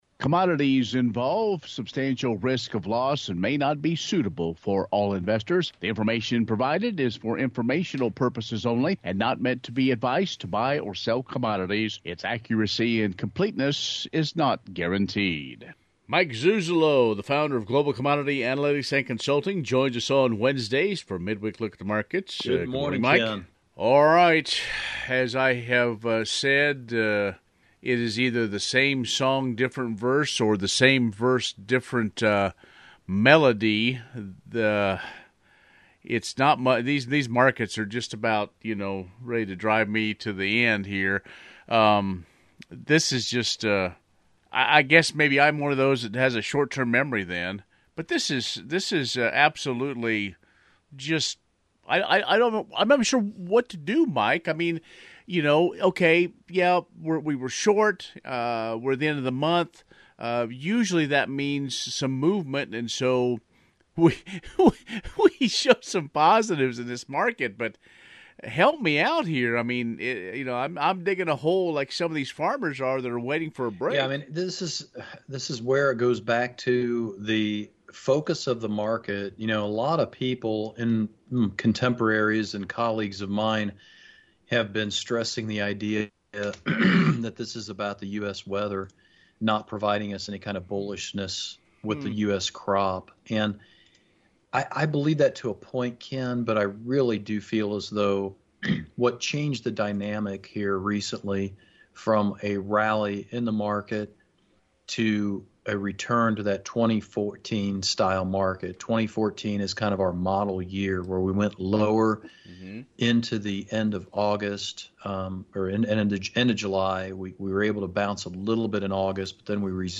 Midweek Market Interview